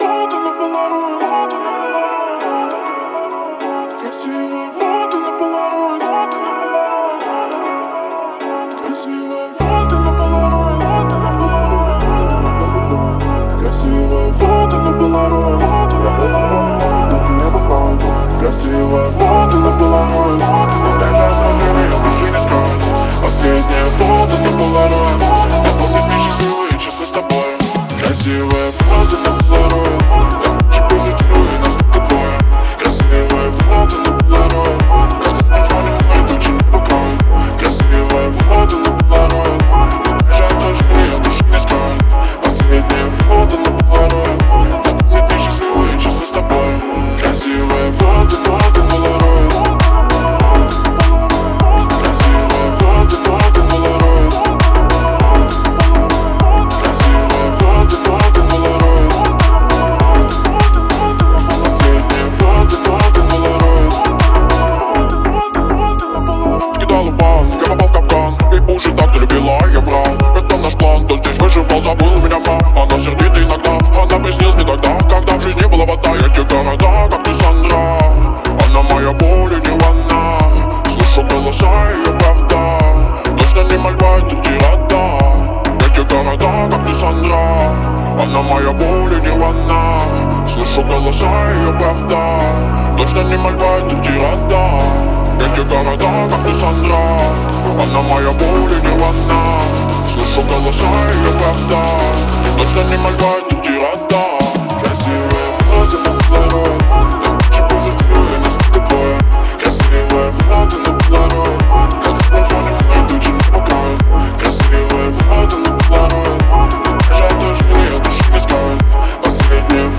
электронная композиция